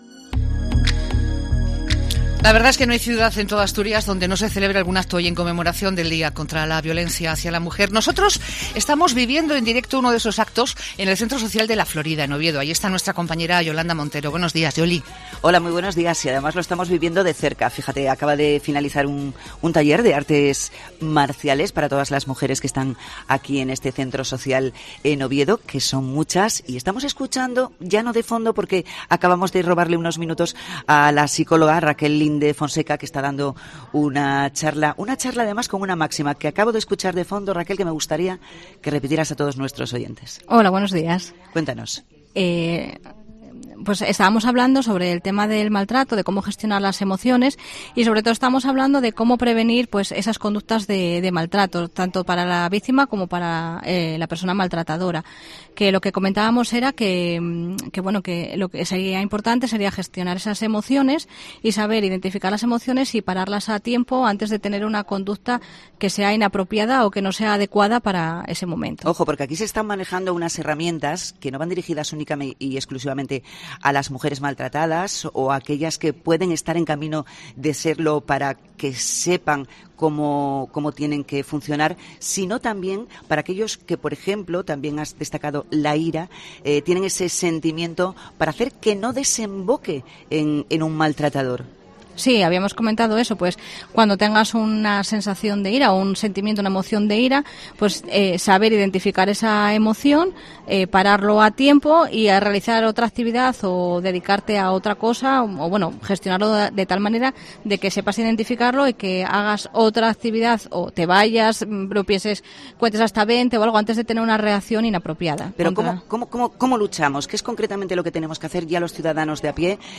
Programa Especial desde el Centro Social de La Florida